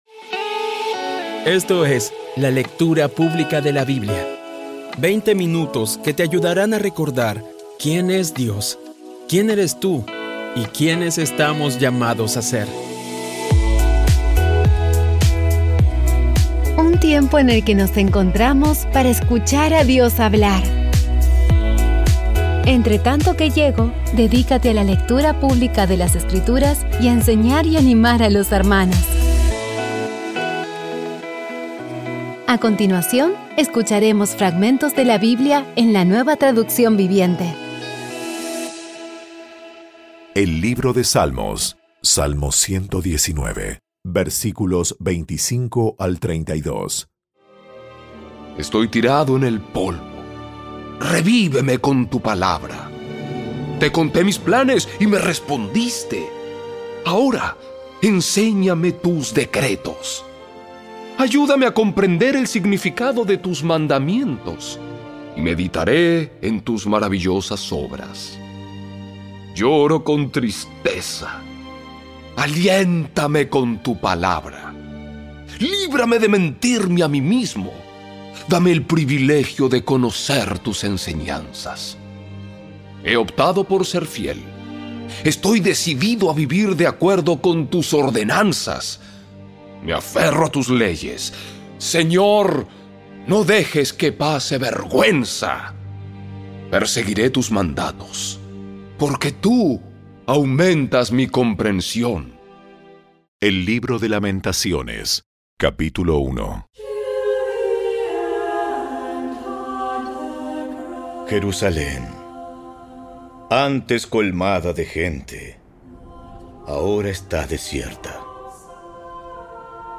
Audio Biblia Dramatizada Episodio 299
Poco a poco y con las maravillosas voces actuadas de los protagonistas vas degustando las palabras de esa guía que Dios nos dio.